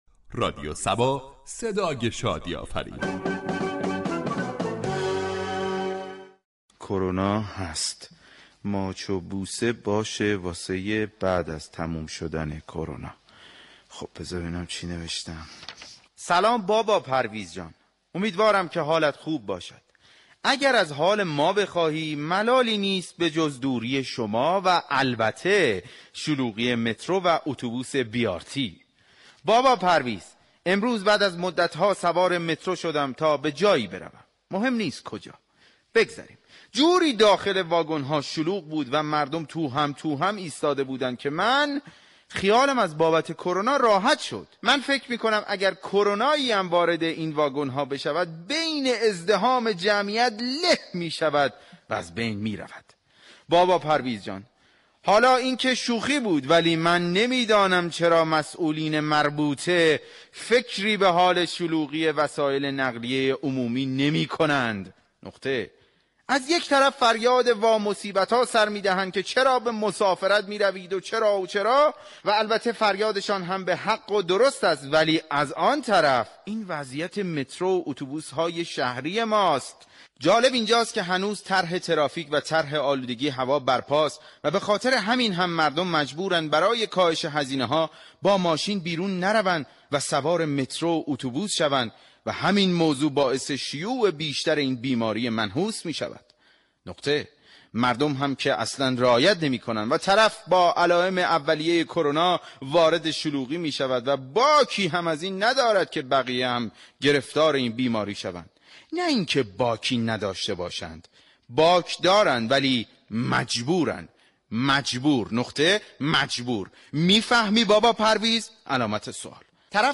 در بخش نمایشی شهر فرنگ با بیان طنز به موضوع دید و بازدیدها در ایام كرونا پرداخته شده است ،در ادامه شنونده این بخش باشید.